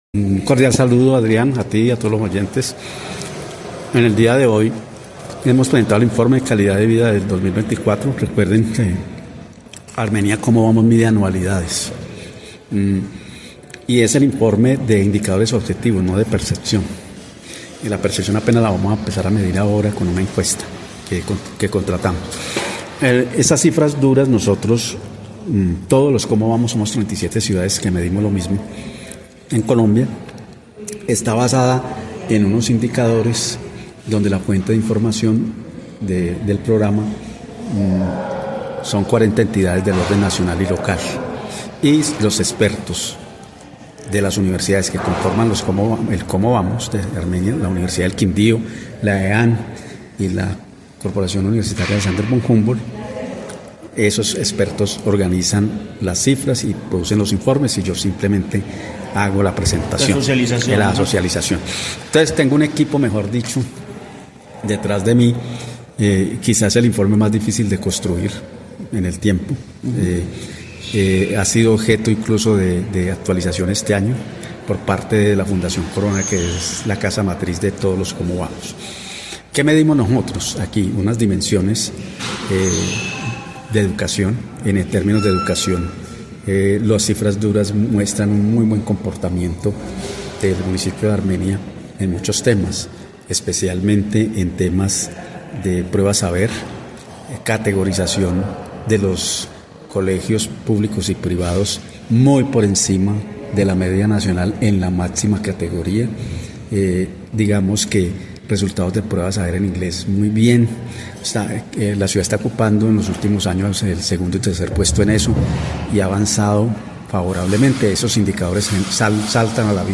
En Caracol Radio Armenia hablamos con el ingeniero